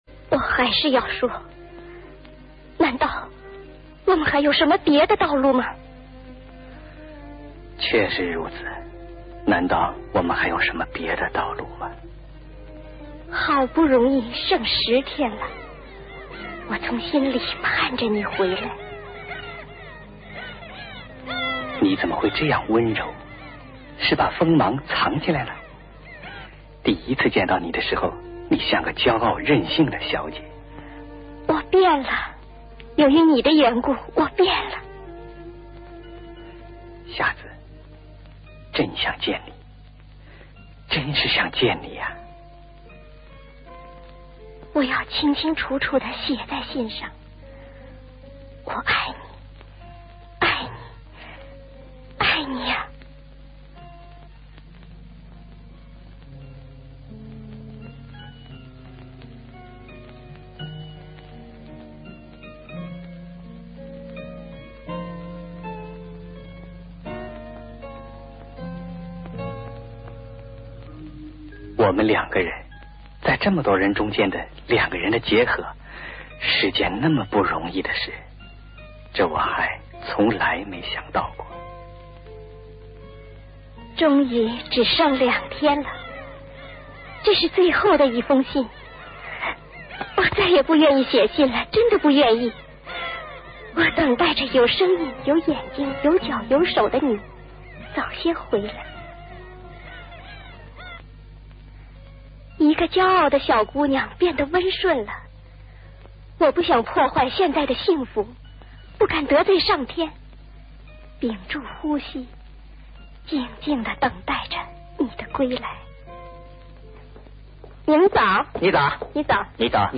【译 制】上海电影译制厂译制（上译）